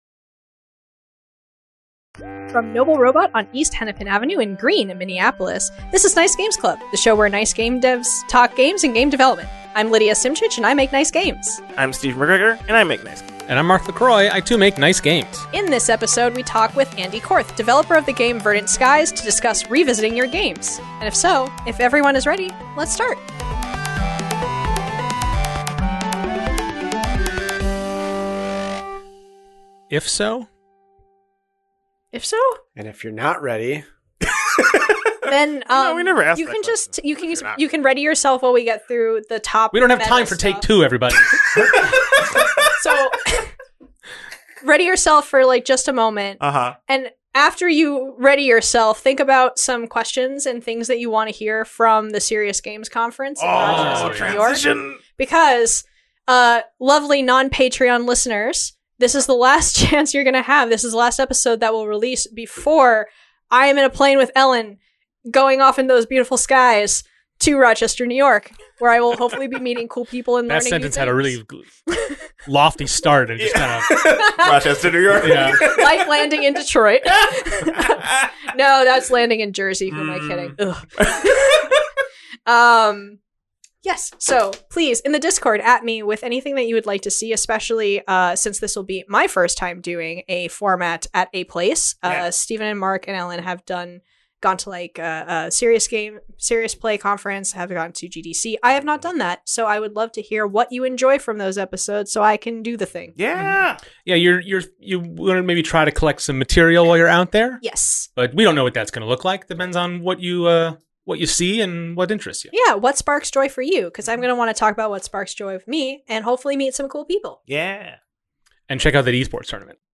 Your Nice Hosts are still recording remotely while we physically distance ourselves! We talk about our new normal and give each other advice on Working From Home and Games to Play while we stay inside.